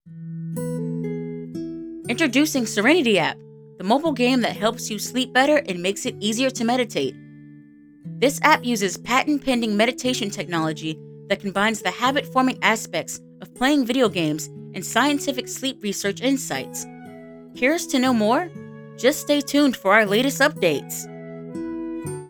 Commercial
Narration: Warm, empowering, or slyly sarcastic—perfect for explainers, audiobooks, and TTS
Professional-grade equipment with broadcast-ready audio
Treated studio space for clean, noise-free recordings
Explainer.mp3